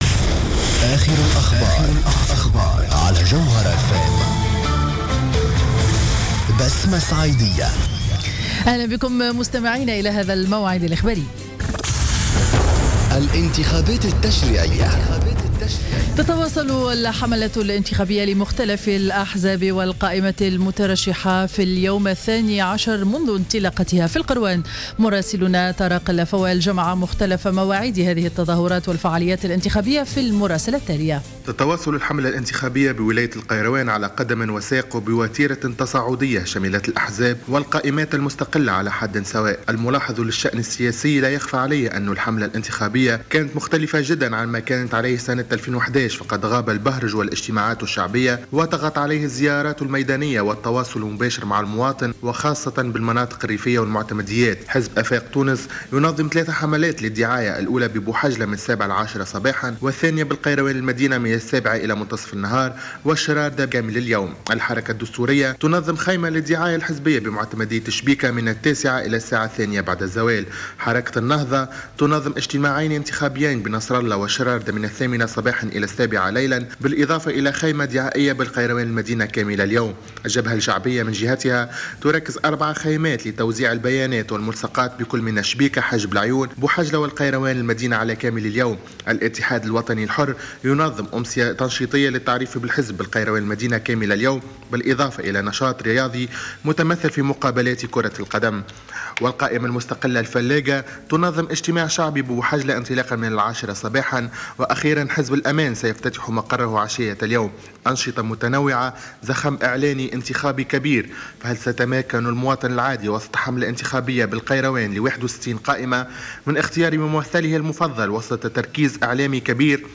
نشرة أخبار منتصف النهار ليوم الأربعاء 15-10-14